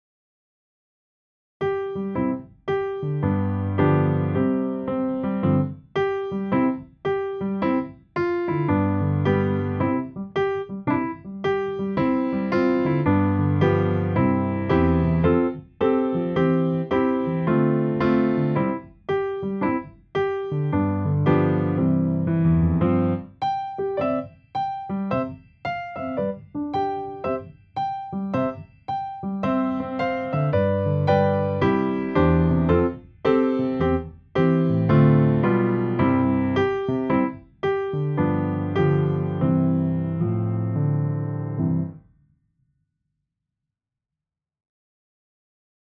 Backing -